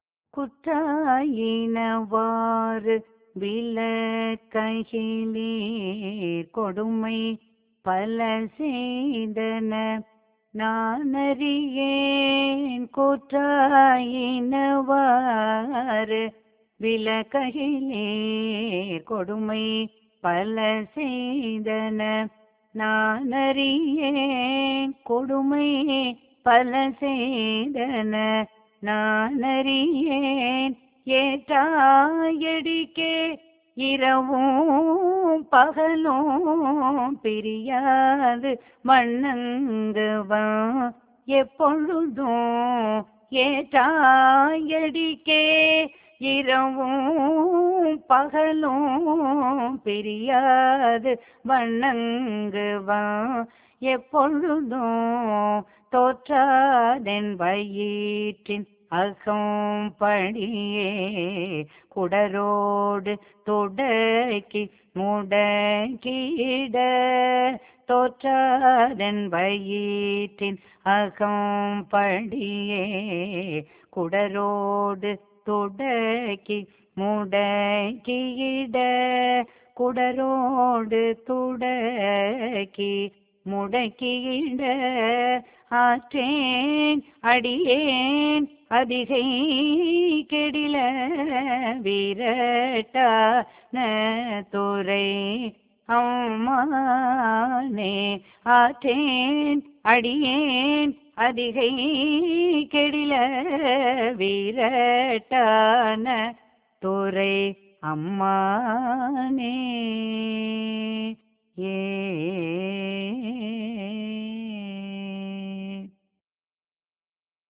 பண்: கொல்லி